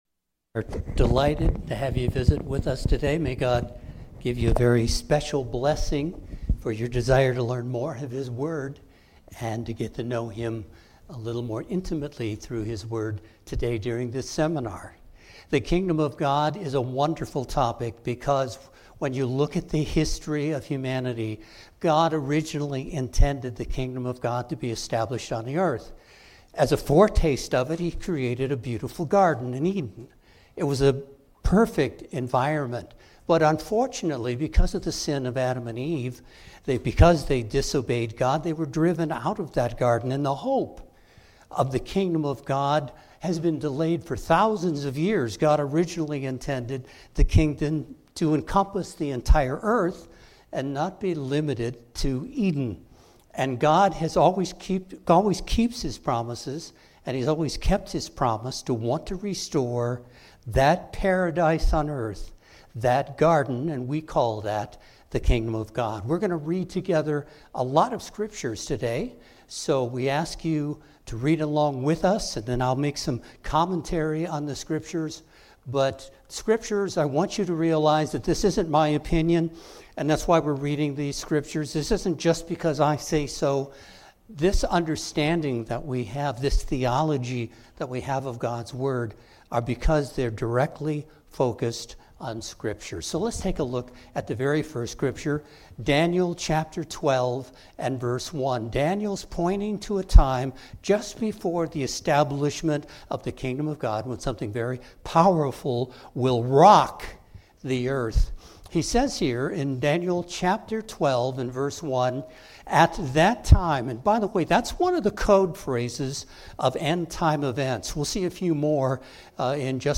Musical performance